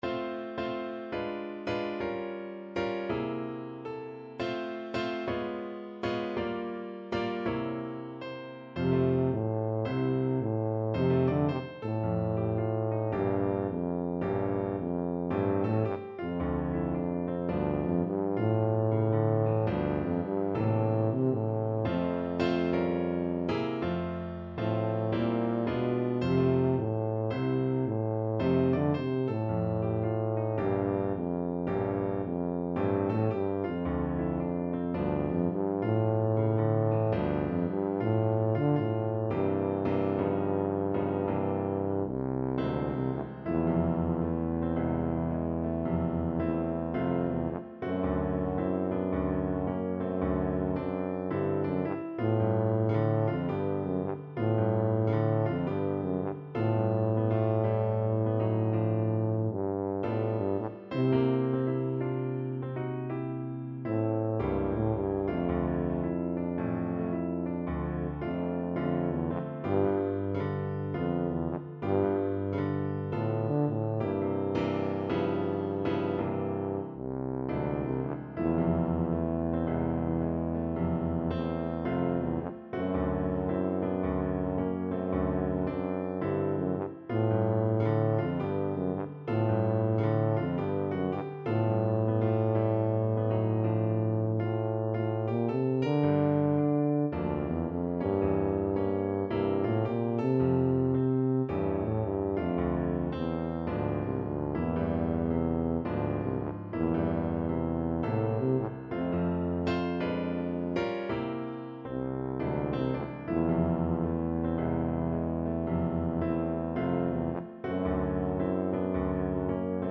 F major (Sounding Pitch) (View more F major Music for Tuba )
~ = 110 Moderate swing